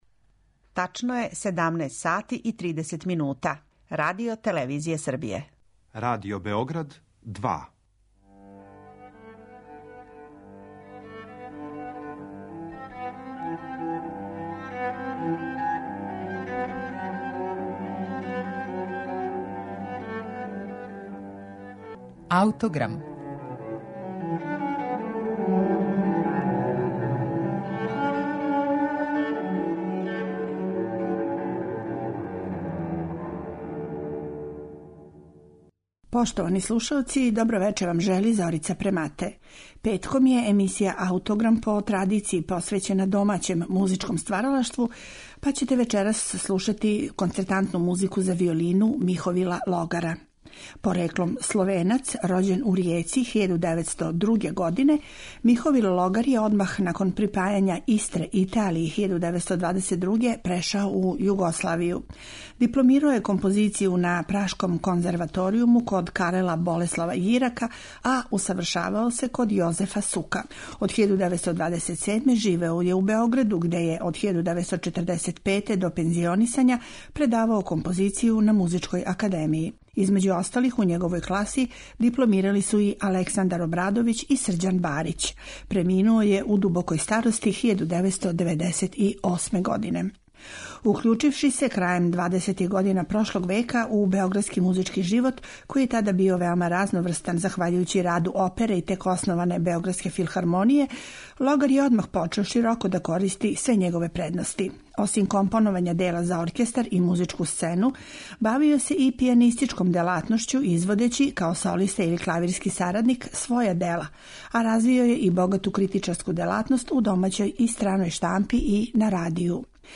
за виолину и оркестар